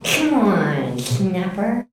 COMEONSNAP.wav